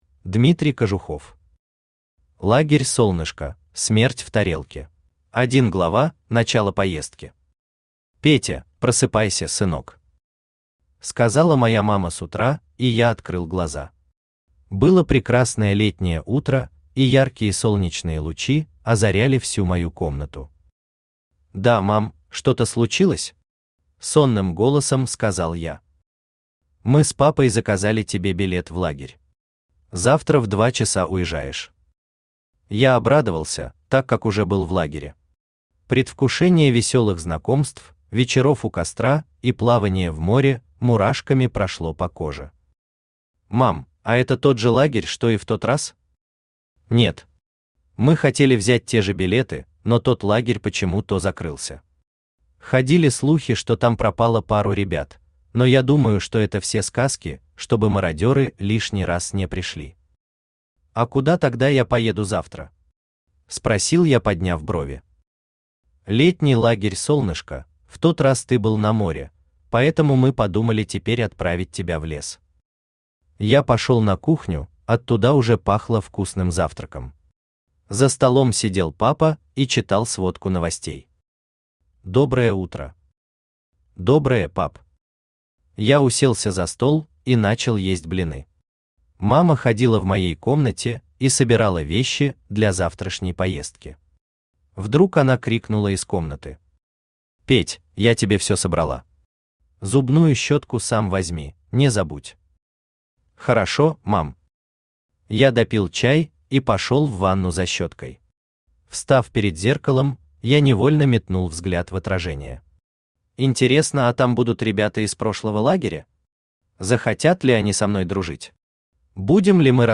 Аудиокнига Лагерь солнышко: смерть в тарелке | Библиотека аудиокниг
Aудиокнига Лагерь солнышко: смерть в тарелке Автор Дмитрий Кожухов Читает аудиокнигу Авточтец ЛитРес.